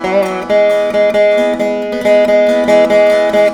135  VEENA.wav